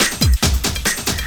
04LOOP05SD-R.wav